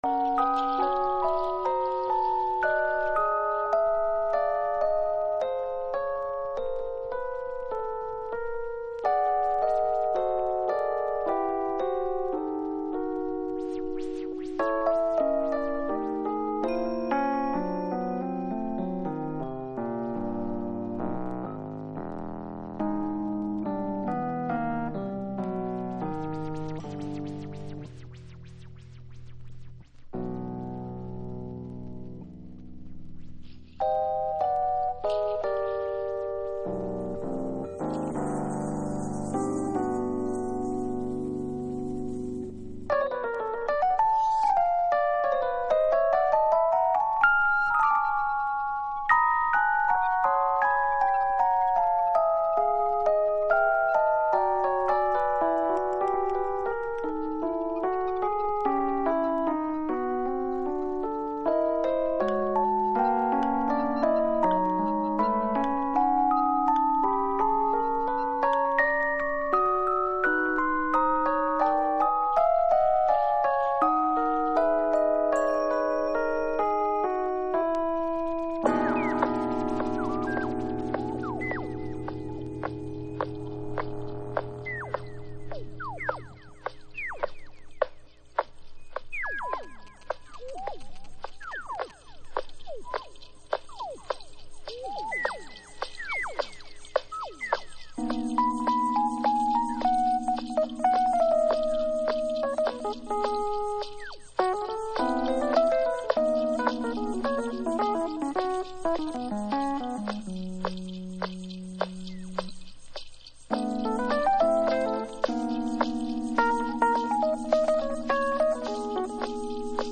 TOP > Future Jazz / Broken beats > VARIOUS